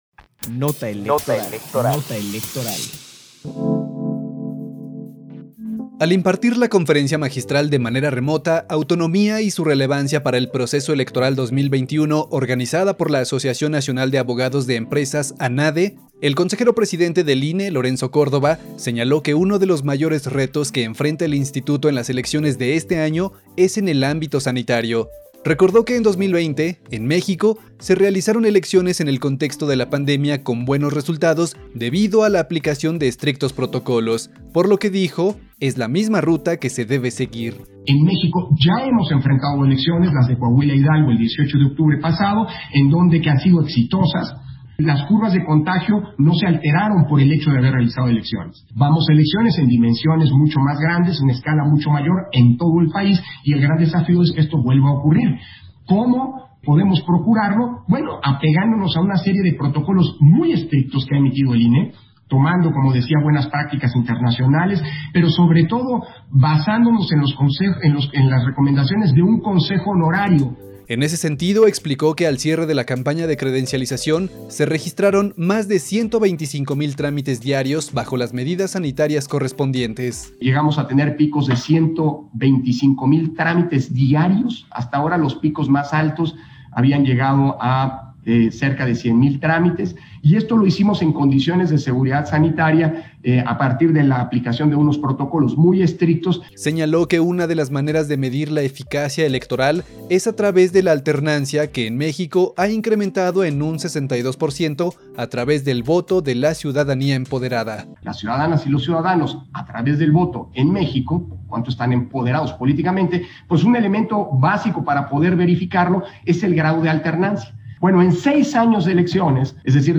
PD CONFERENCIA LCV ANADE